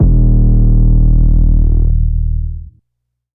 808 (Drive The Boat).wav